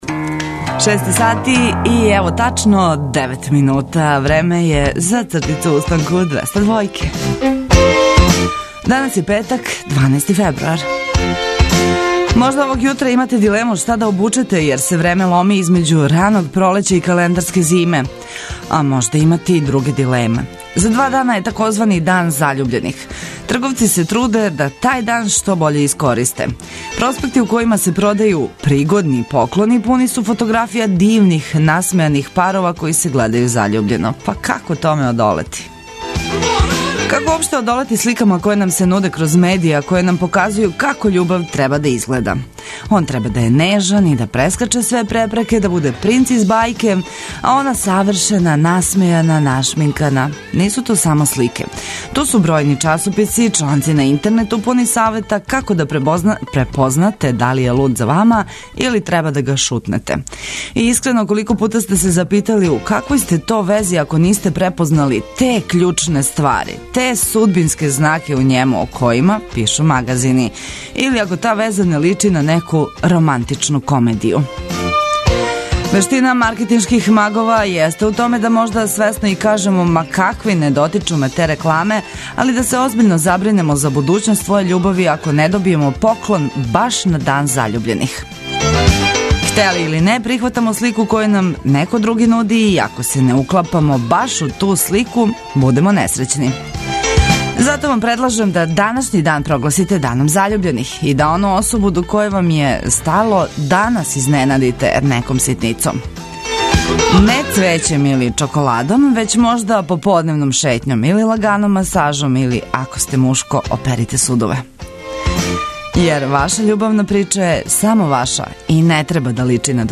Јутарњи програм Београда 202.